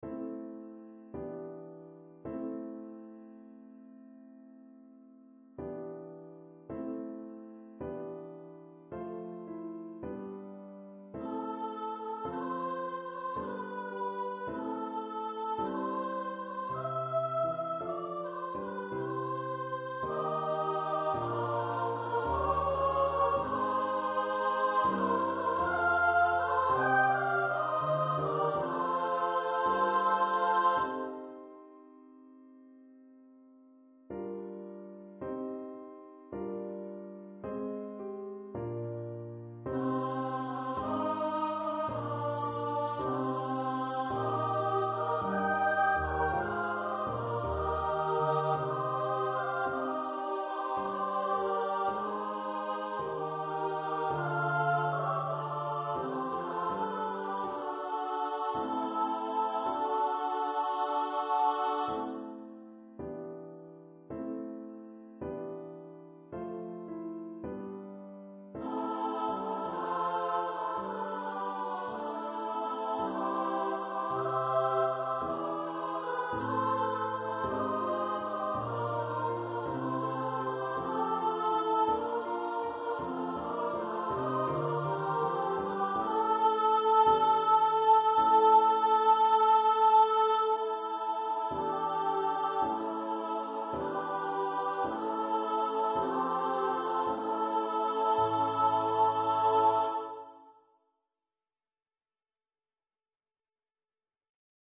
for upper voice choir and piano
Scored for SSA choir with piano accompaniment.